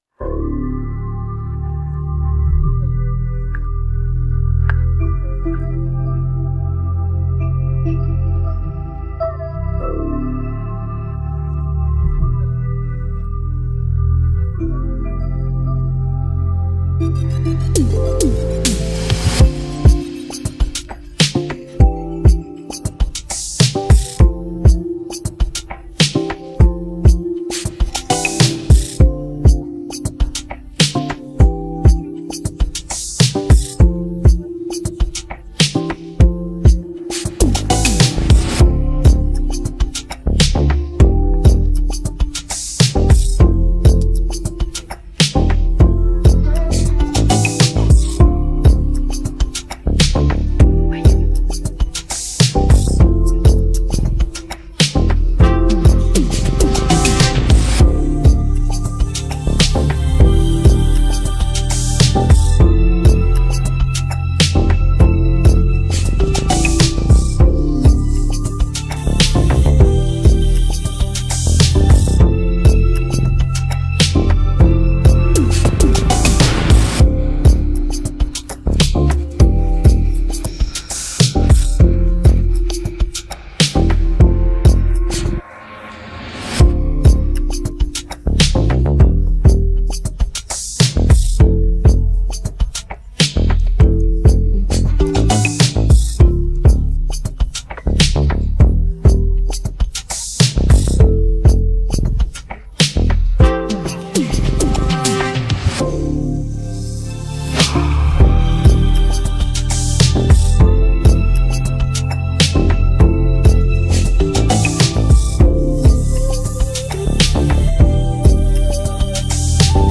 Highlife
Tagged afrobeats